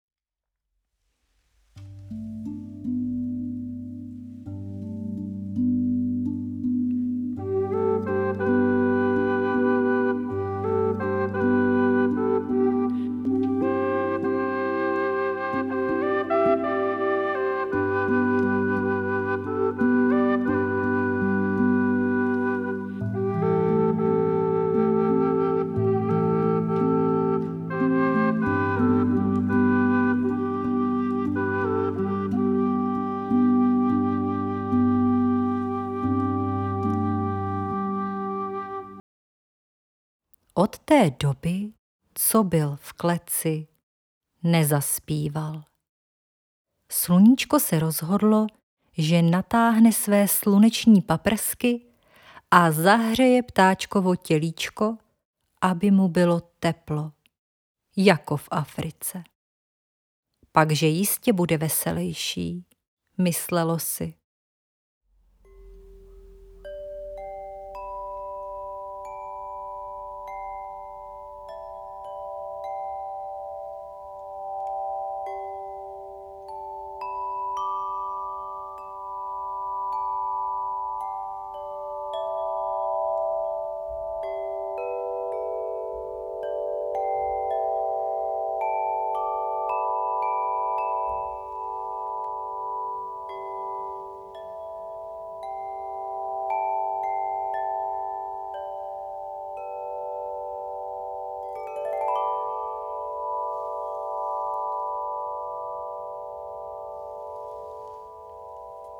Muzikoterapeutická pohádka, která propojuje prvky vyprávění a hudebního doprovodu netradičních terapeutických nástrojů. Využívá sílu jednoduchého příběhu, hudby i zvukových podnětů k vytvoření bezpečného prostoru, kde může posluchač prožívat, představovat si a reagovat na podněty v souladu se svými individuálními potřebami.
Audio ukázka pohádky